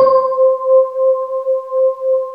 Index of /90_sSampleCDs/USB Soundscan vol.28 - Choir Acoustic & Synth [AKAI] 1CD/Partition D/08-SWEEPOR